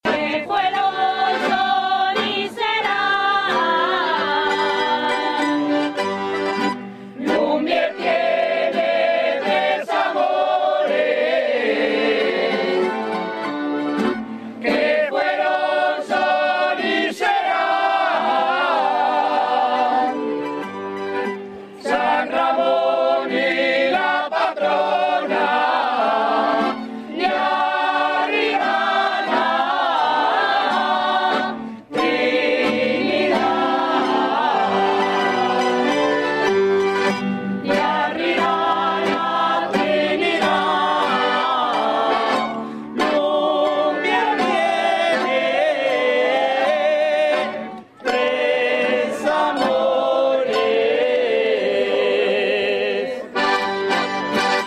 Al llegar a la ermita, penitentes y romeros celebran y cantan la misa acomodados en la explanada exterior de la ermita o diseminados en el bojeral de la ladera.
La misa finaliza con una jota popular dedicada a la Trinidad, que dice así: